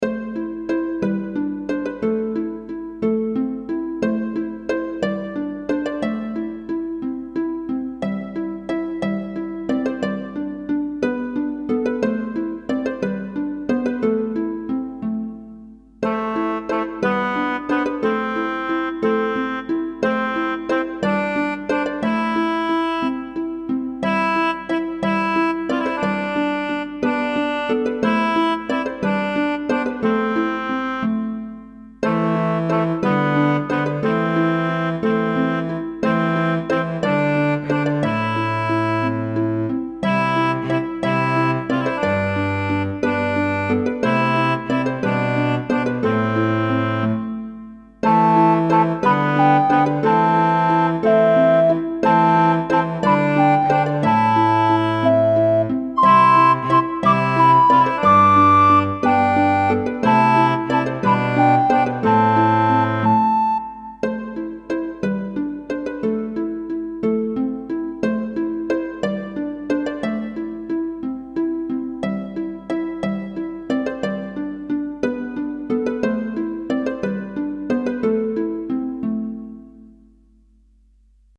Pieza de aire renacentista
música renacentista